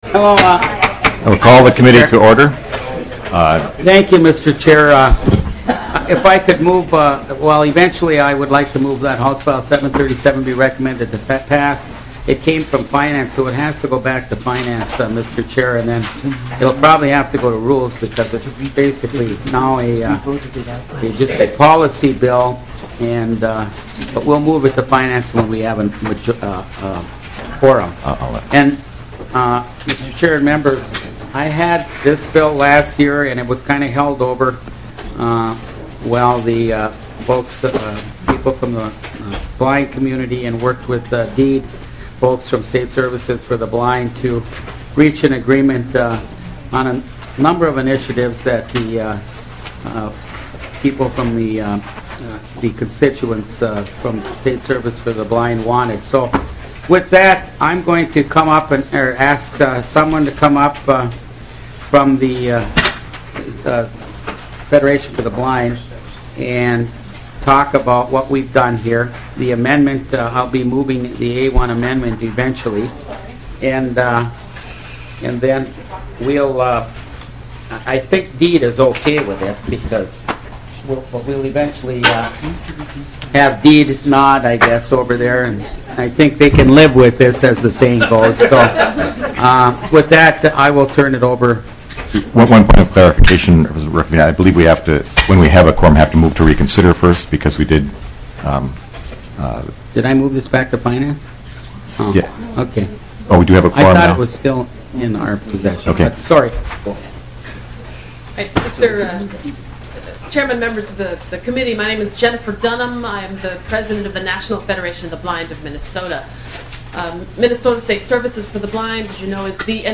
03:53 - Gavel, and consideration of HF737 (Rukavina) Rehabilitation counselors for the blind certification provided.